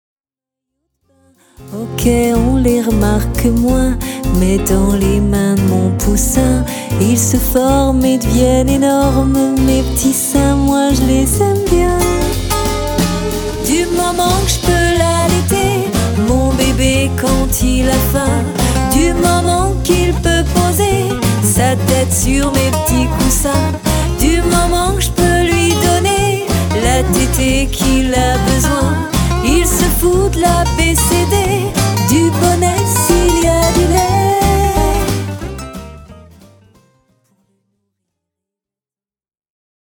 Une voix qui enchante...